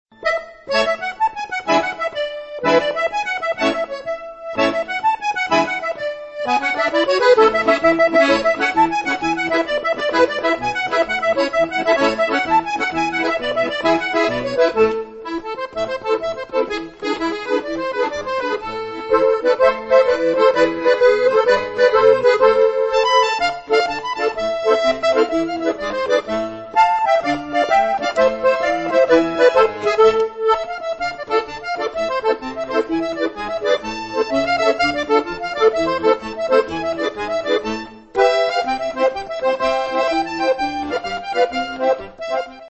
Rock, jazz, polcas, bailes de Auvernia (bourrées), valses, java, tango, madison, fox-trot, gaita, folk, musicas Tex-Mex, cajun y zydéco, musicas traditionnales, swing, gitanos y swing-musette, etc; el accordeón se presta de buen grado a todas las clases musicales.
tarantella.mp3